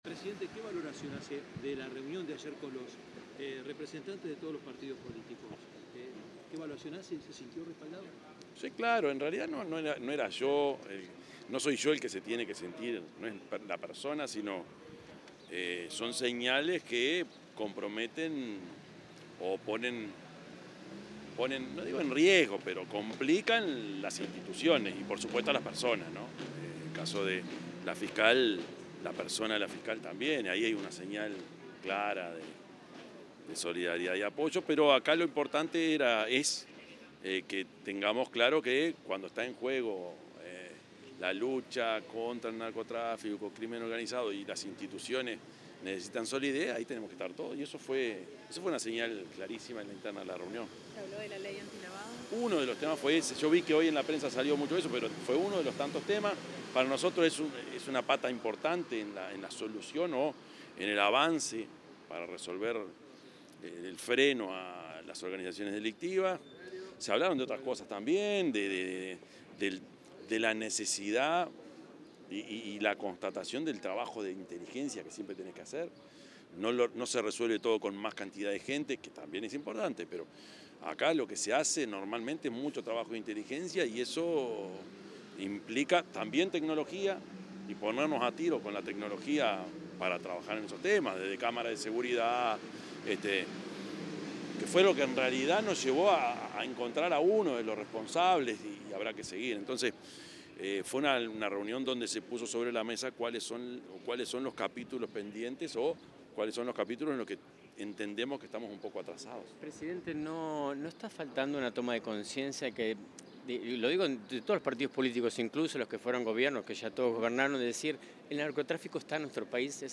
Declaraciones del presidente Yamandú Orsi
El presidente de la República, Yamandú Orsi, efectuó declaraciones a la prensa acerca de las reuniones que mantuvo el lunes 29 de setiembre en la